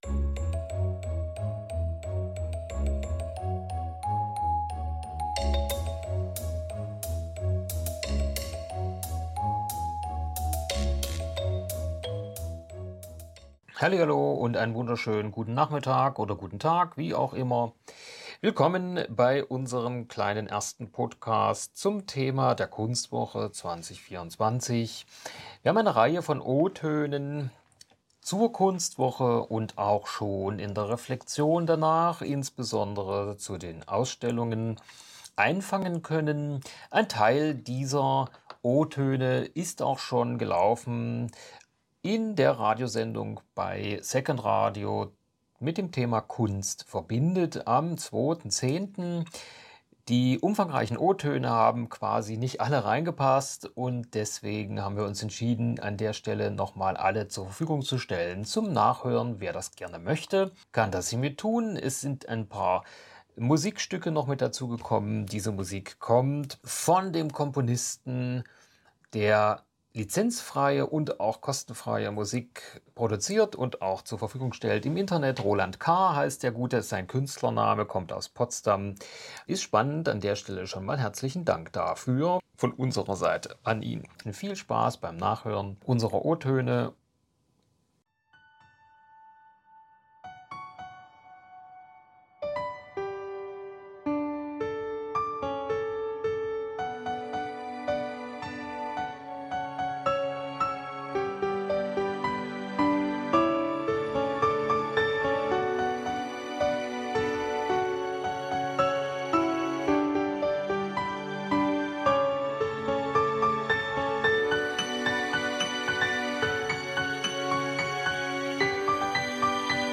podcast-kunstwoche-eb-o-toene-2.mp3